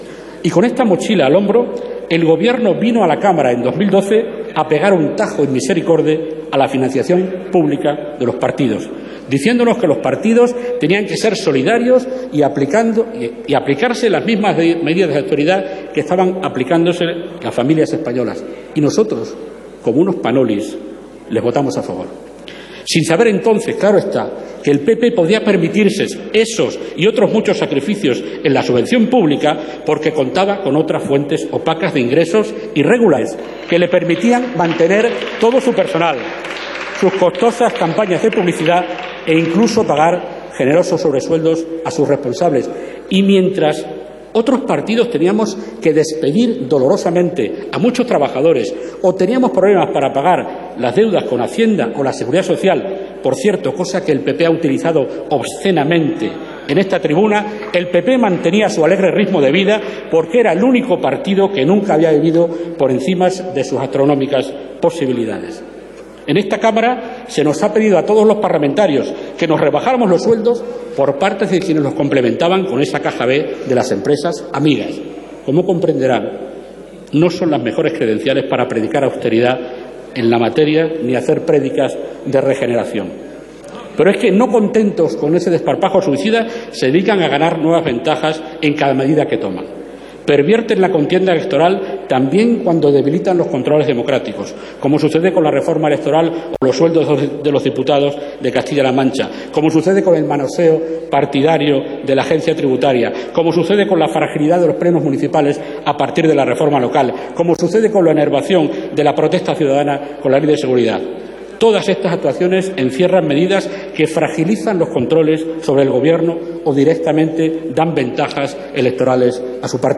Fragmento de la intervención de Nacho Sánchez Amor en el pleno del 10/12/2013 en el que defiende la proposición de ley para prohibir a las empresas realizar donativos a los partidos políticos